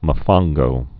(mə-fŏnggō, mō-fōn-)